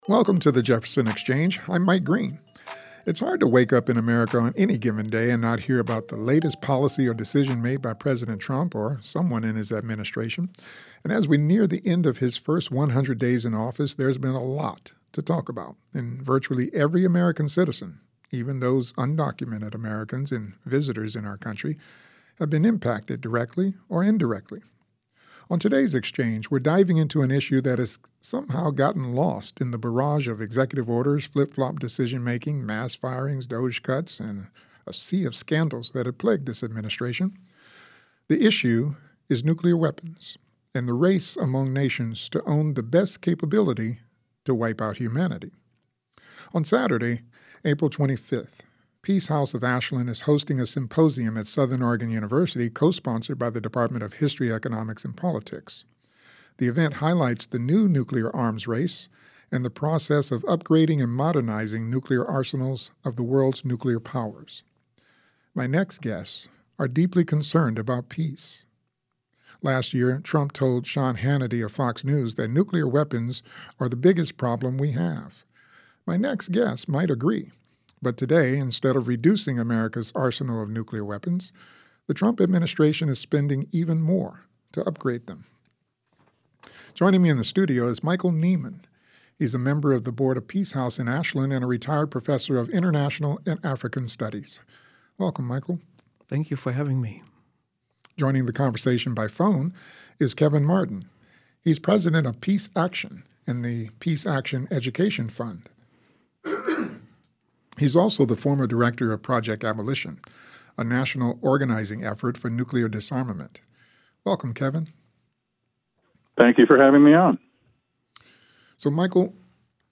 Play Rate Apps Listened List Bookmark Share Get this podcast via API From The Podcast The Jefferson Exchange 1 JPR's live interactive program devoted to current events and newsmakers from around the region and beyond.